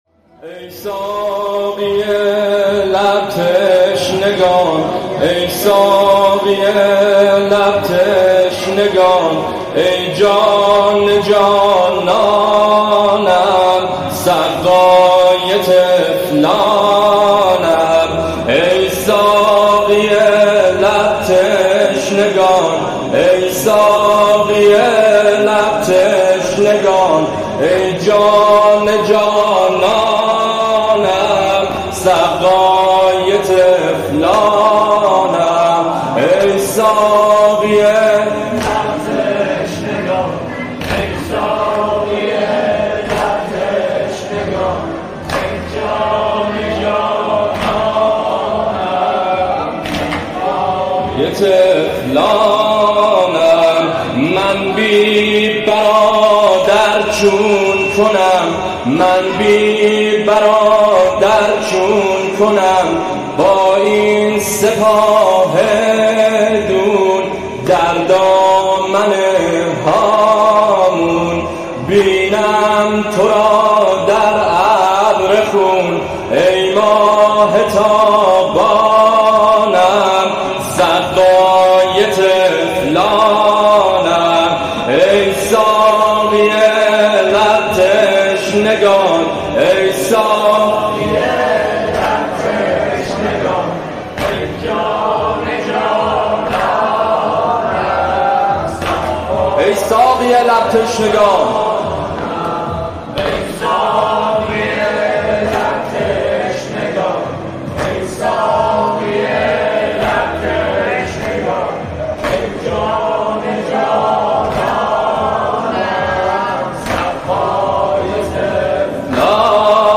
نوحه سنتی ای ساقی لب تشنگان ای جان جانانم سقای طفلانم